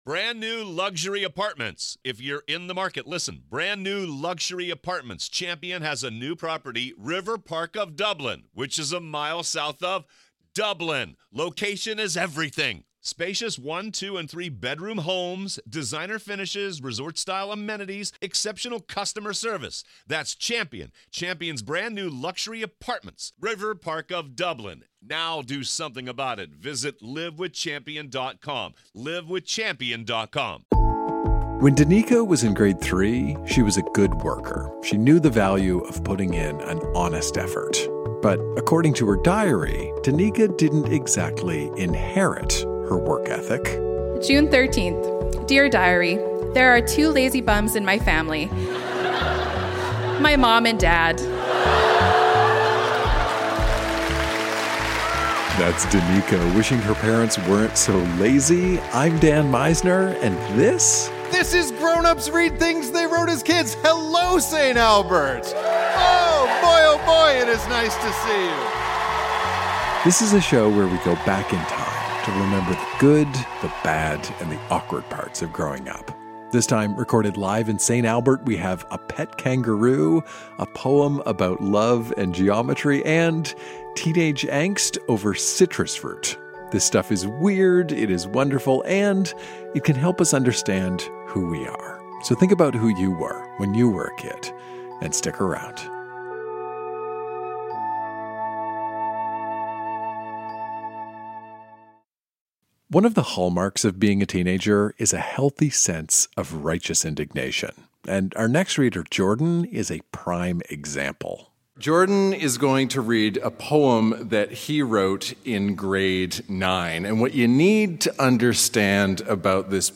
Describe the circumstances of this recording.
Recorded live at The Arden Theatre in St. Albert.